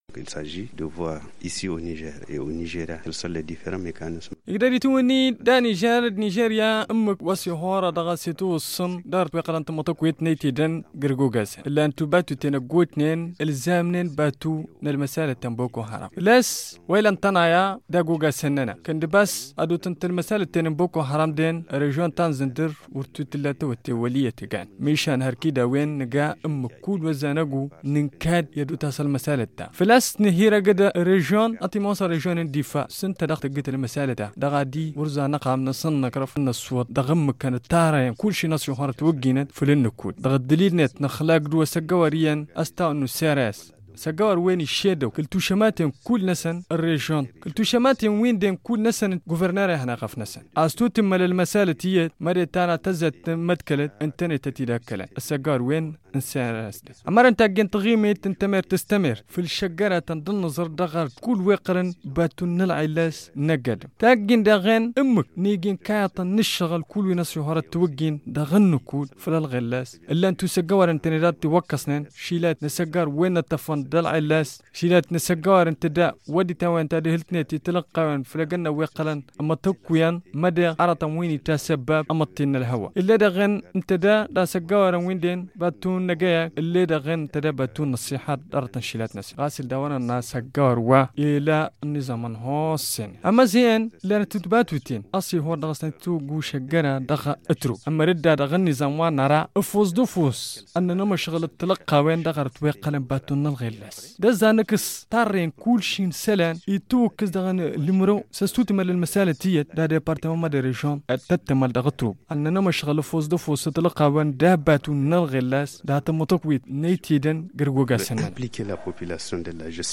Objectif : partager leurs expériences sur les outils pour la gestion des mouvements de personnes. Explications d’Issa Moussa, Gouverneur de la région de Zinder